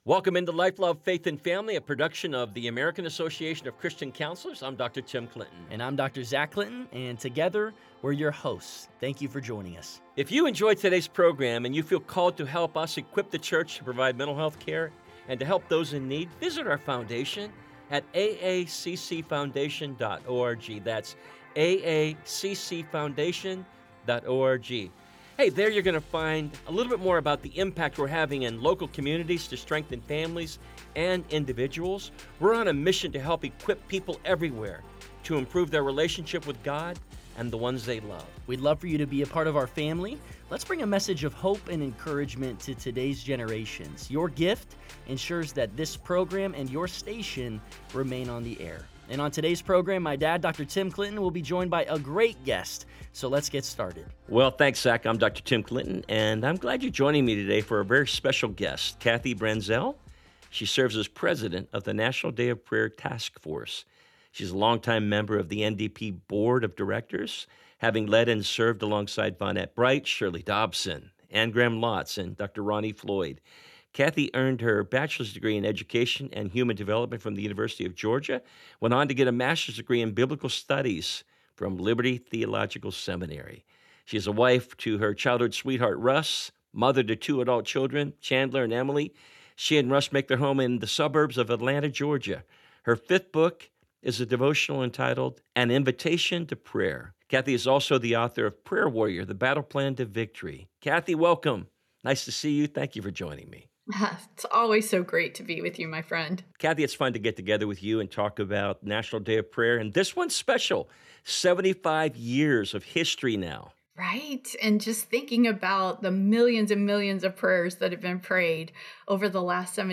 This conversation offers comfort and hope to those nearing life’s end or walking beside a loved